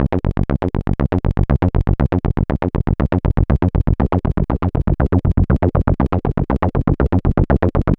Session 08 - Bass 02.wav